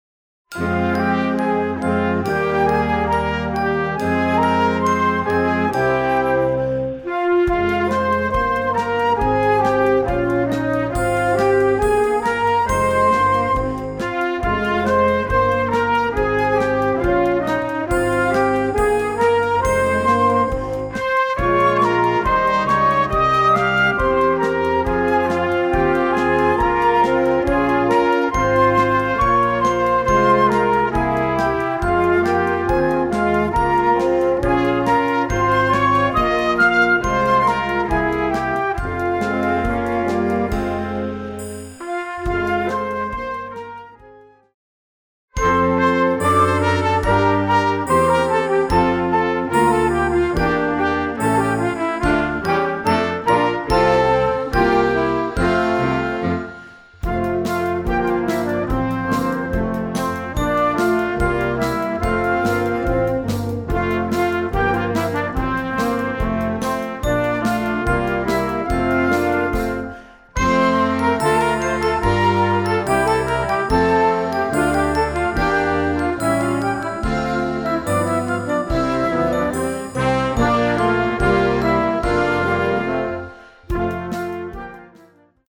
Gattung: Weihnachtsmusik für Jugendblasorchester
Besetzung: Blasorchester